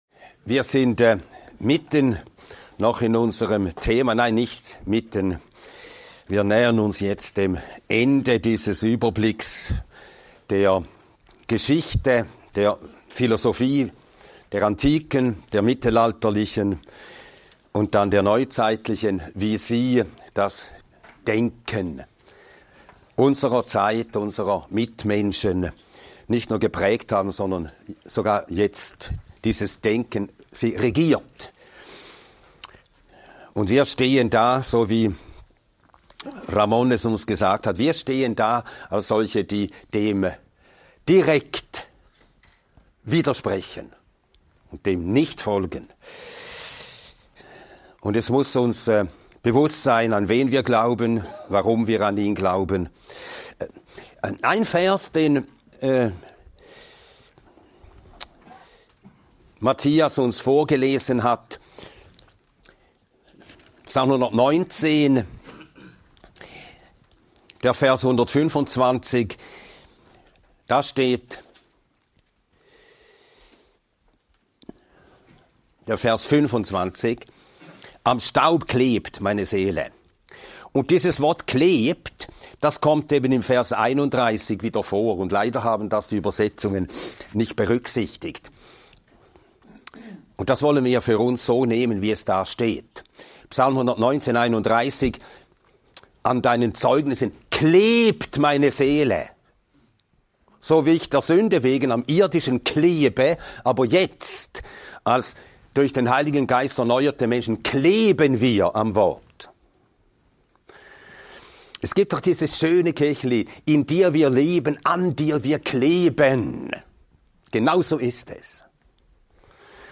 In diesem Vortrag erfährst du, warum die Suche nach Wahrheit in der Postmoderne immer in Verzweiflung endet. In der Postmoderne werden verbindliche Wahrheiten abgelehnt, alles erscheint relativ, und der Mensch verliert die Orientierung. Nietzsche und andere Denker zeigen, wohin der Weg ohne Gott führt – in die Leere.